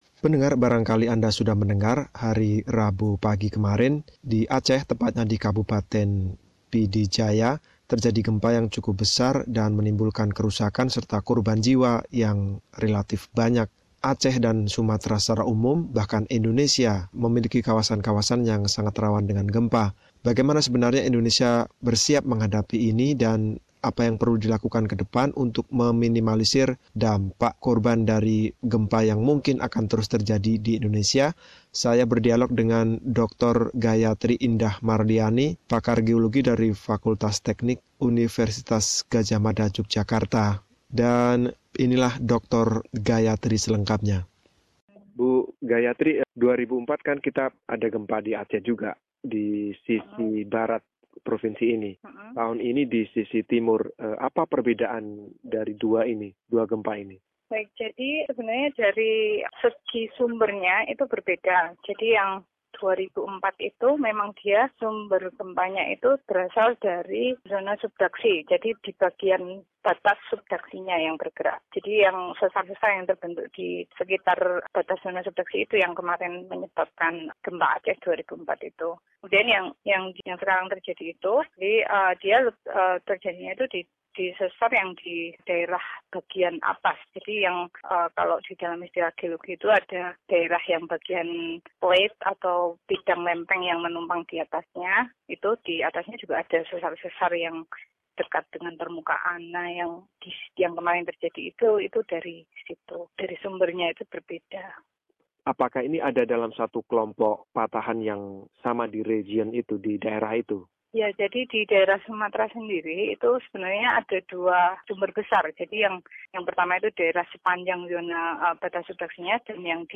Wawancara dengan Pakar gempa dari Universitas Gadjah Mada